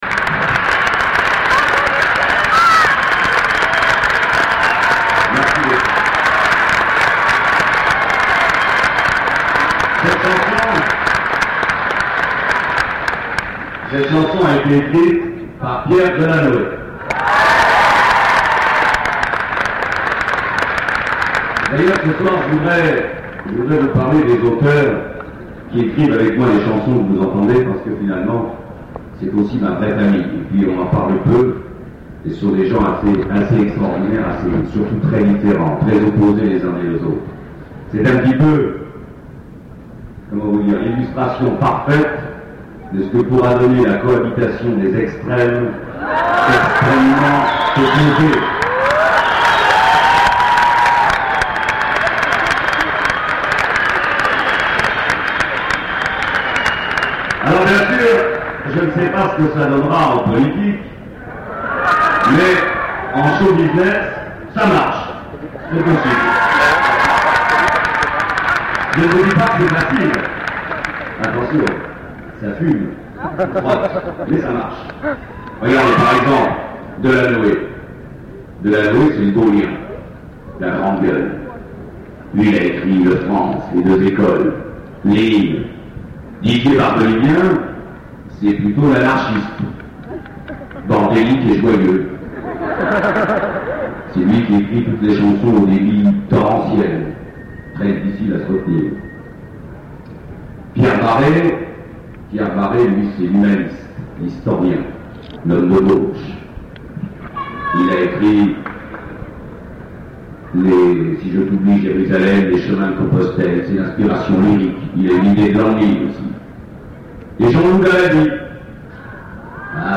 Bootlegs (enregistrements en salle)
Marseille (9 mars 1986)